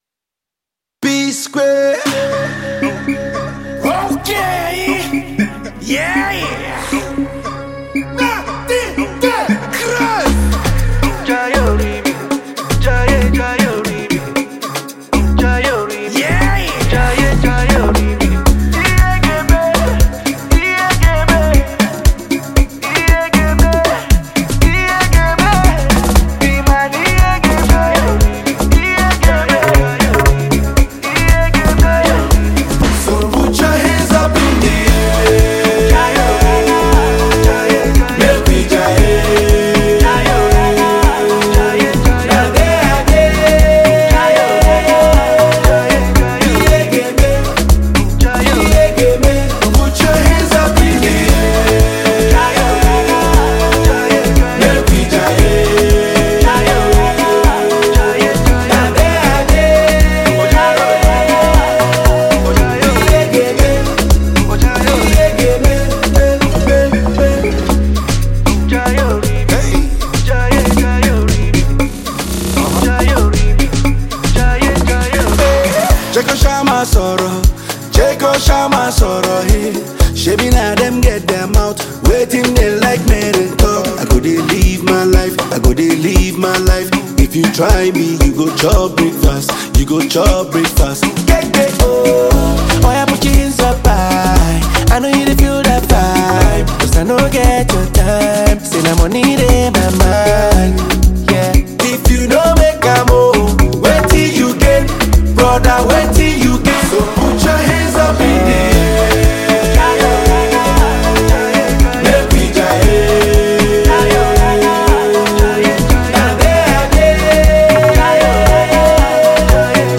Nigerian foremost duo